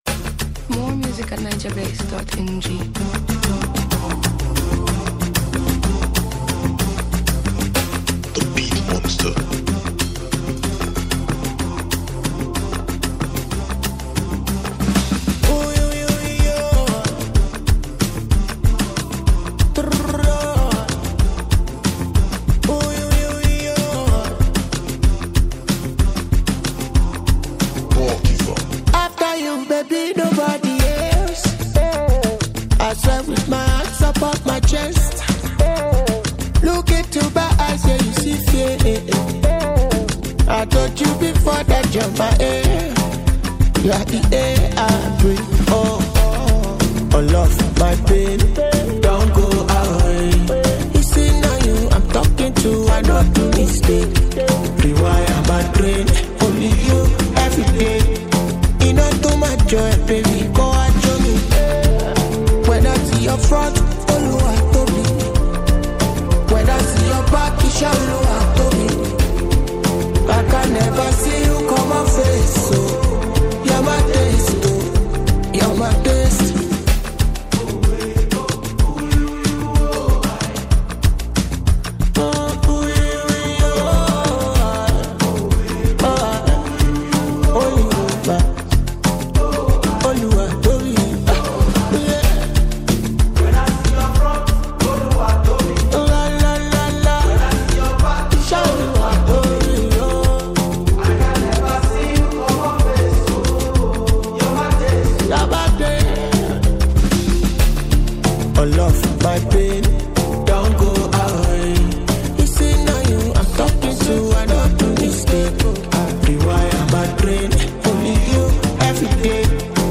delivers a smooth and emotional vibe